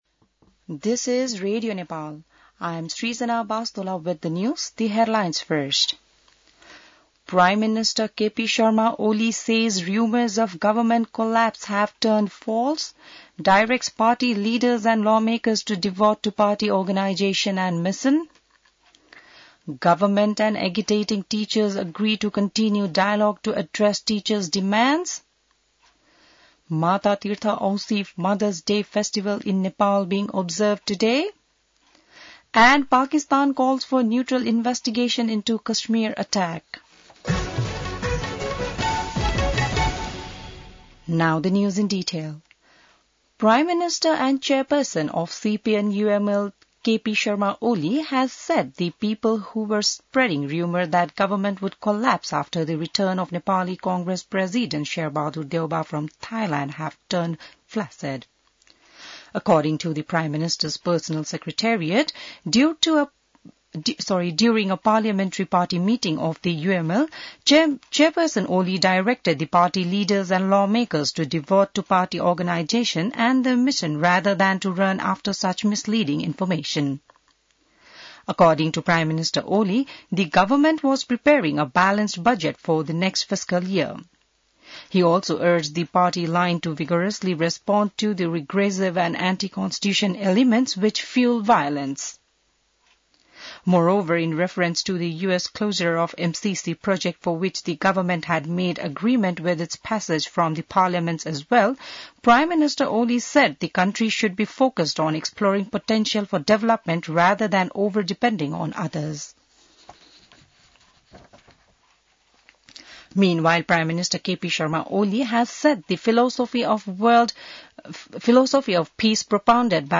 बिहान ८ बजेको अङ्ग्रेजी समाचार : १४ वैशाख , २०८२